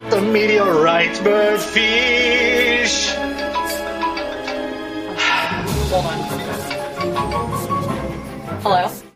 with subsequent ring!